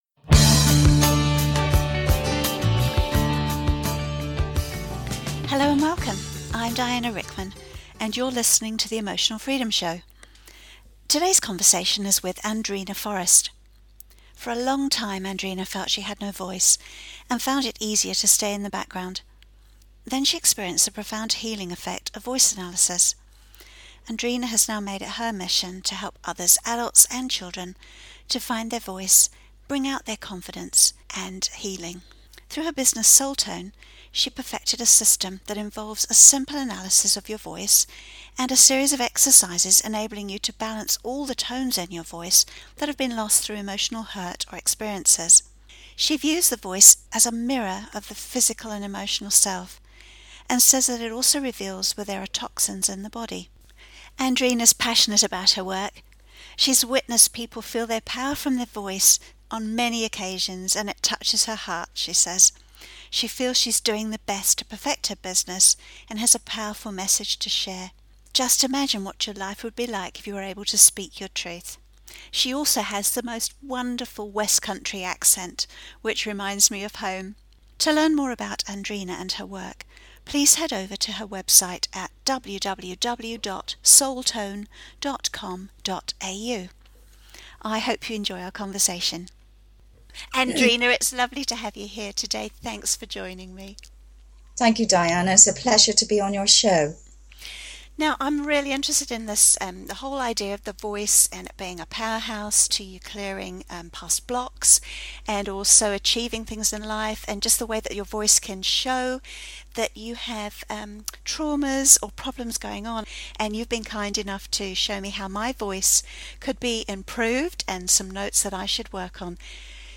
Radio Interviews - New Zealand - Soul Tone
Radio-interview.mp3